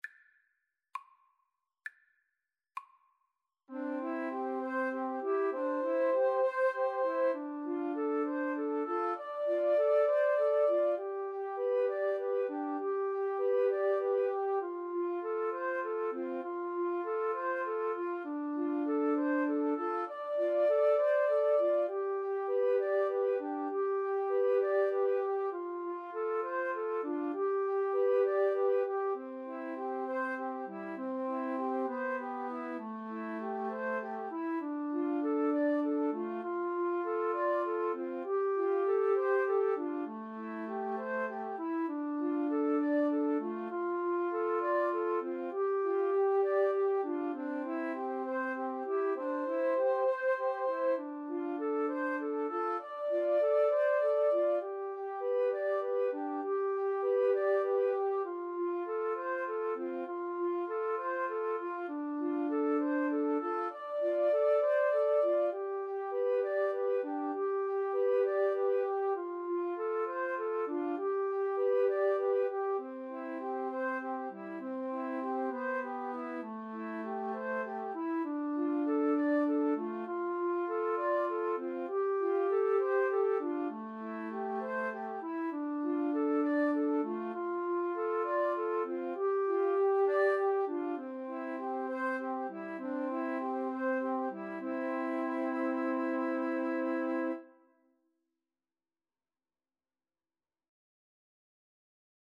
6/8 (View more 6/8 Music)
Classical (View more Classical Flute Trio Music)